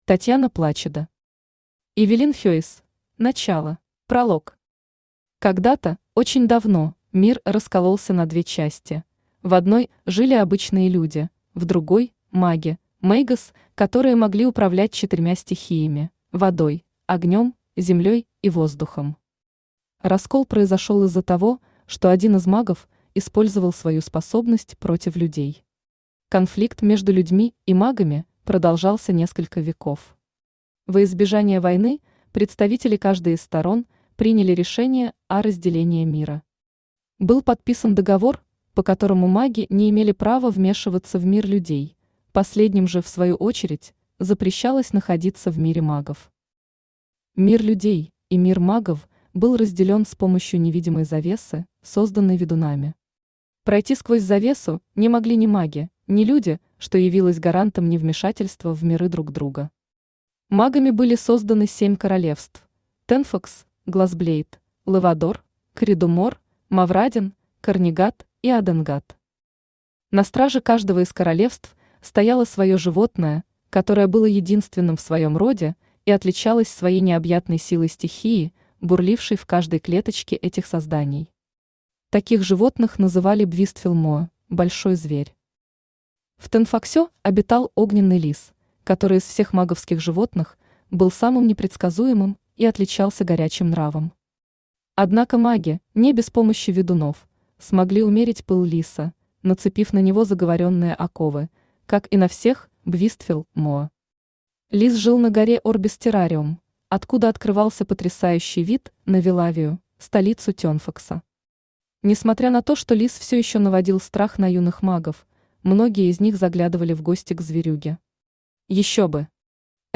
Аудиокнига Эвелин Хейс: начало | Библиотека аудиокниг
Aудиокнига Эвелин Хейс: начало Автор Татьяна Плачидо Читает аудиокнигу Авточтец ЛитРес.